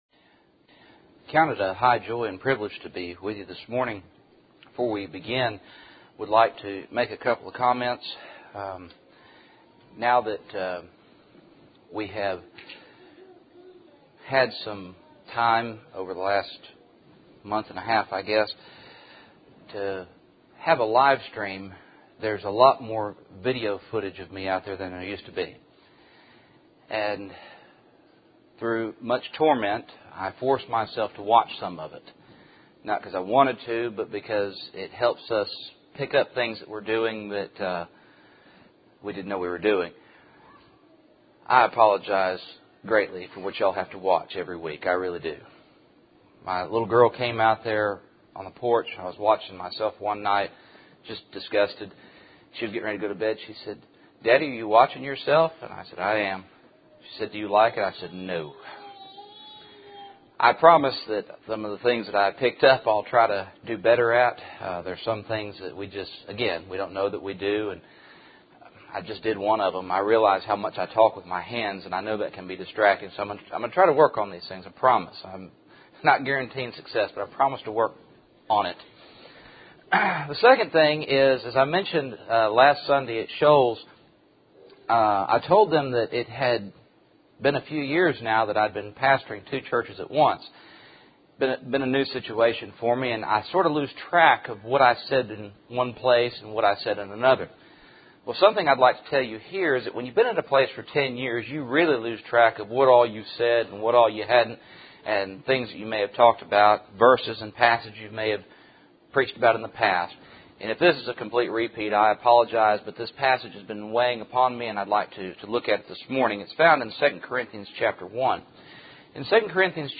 Service Type: Cool Springs PBC Sunday Morning